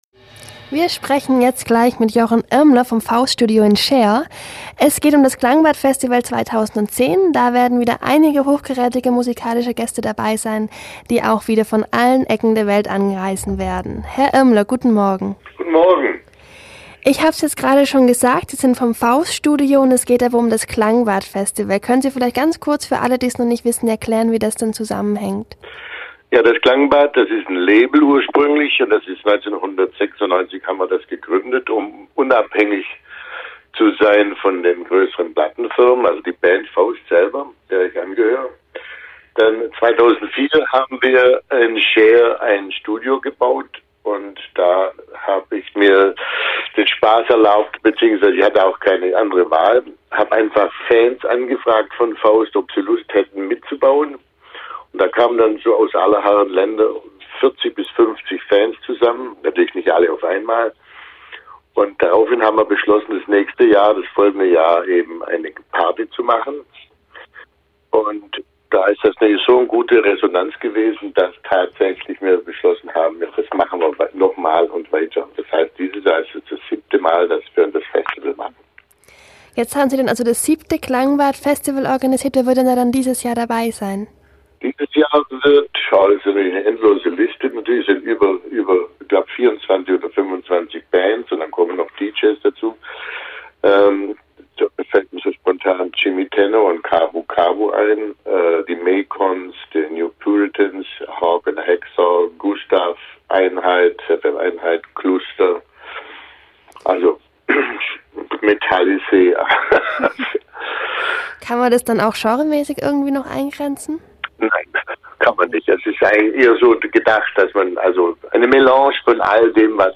Aber wir fragen schon mal nach. Der "Chef" Jochen Irmler im free FM Interview.